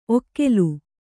♪ okkelu